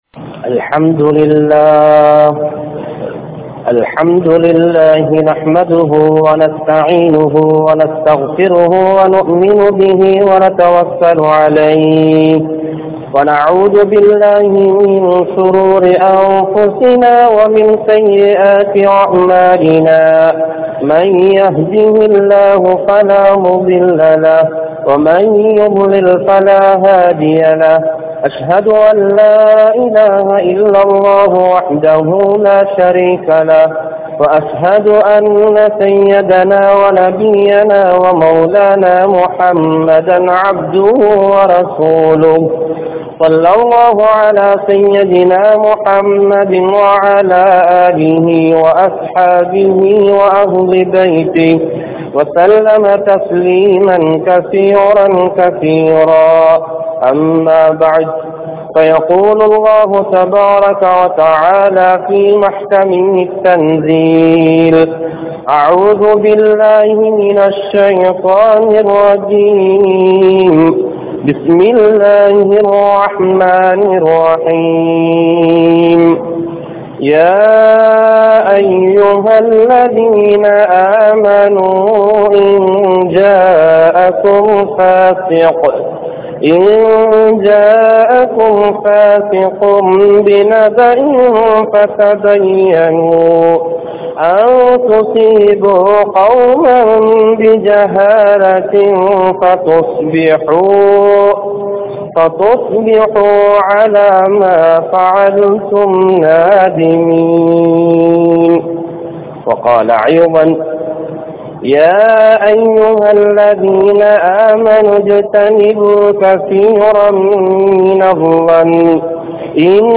Nilamihalai Maattrum Paavangal (நிலமைகளை மாற்றும் பாவங்கள்) | Audio Bayans | All Ceylon Muslim Youth Community | Addalaichenai
Thihari Jumua Masjidh